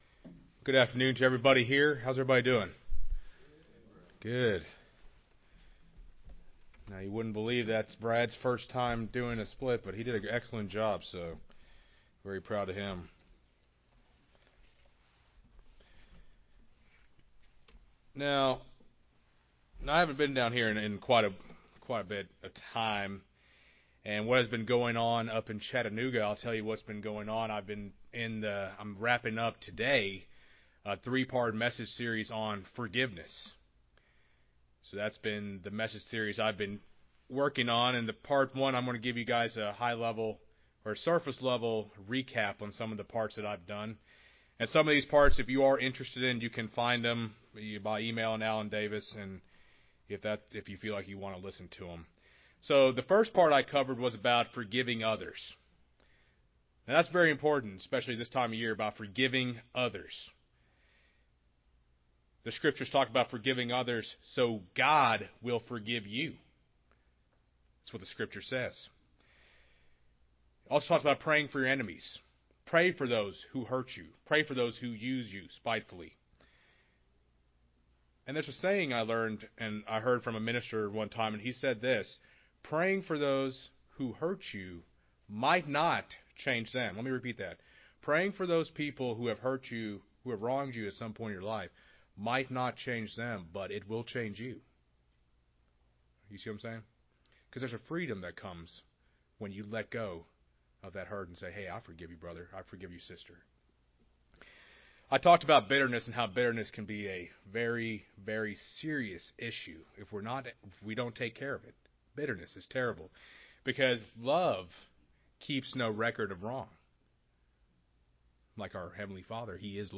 This is the third split sermon in a three part series on forgiveness.
Given in Rome, GA